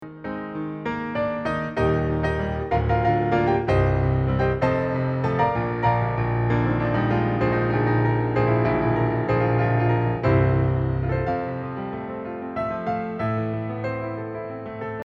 piano versions
reimagined with a more relaxed tone